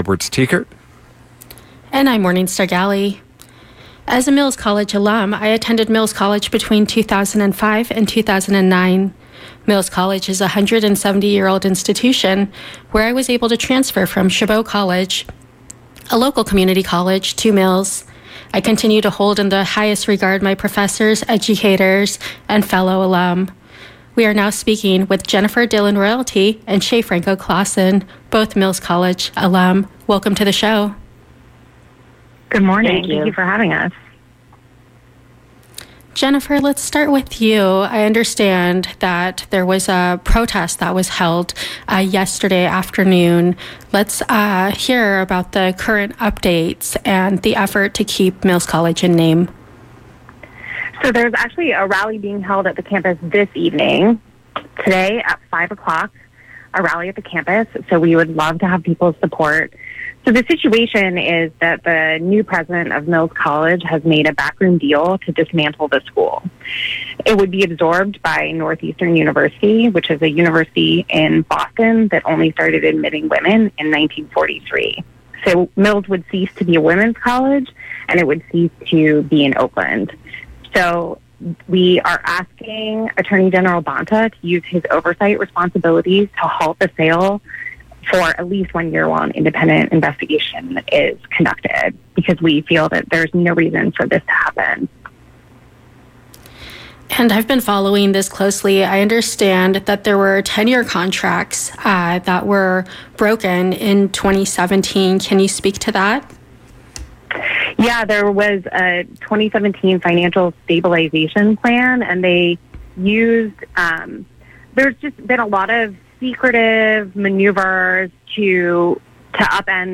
KPFA Interview with Alums